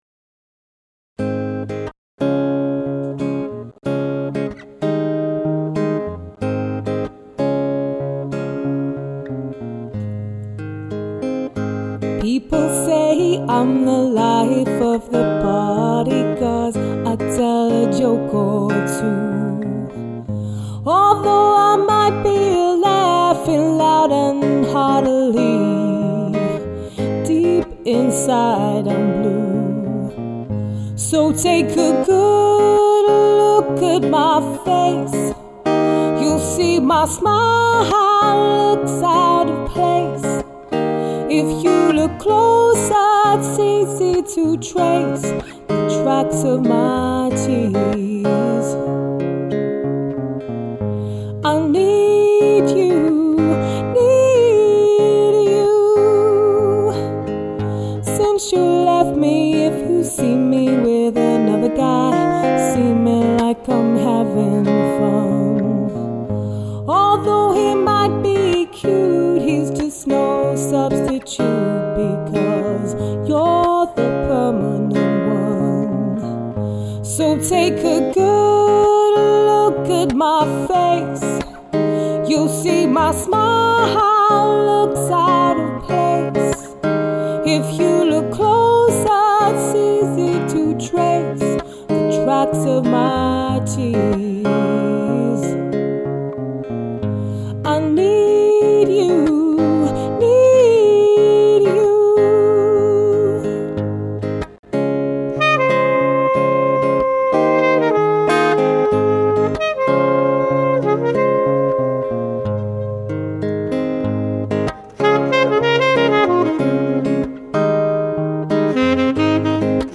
Jazz Singer | Jazz Trio | Jazz Quartet | Jazz Band